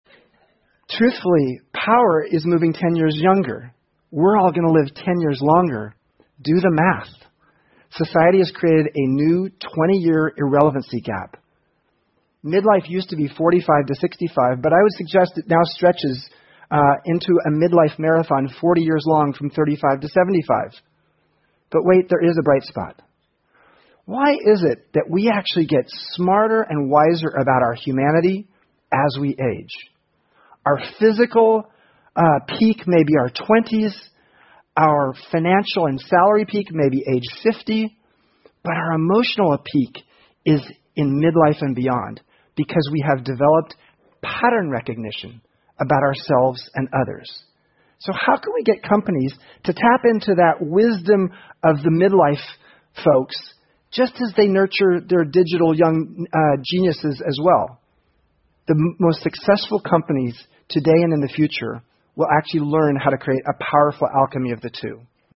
TED演讲:婴儿潮时期的人和千禧一代如何在工作中相互学习() 听力文件下载—在线英语听力室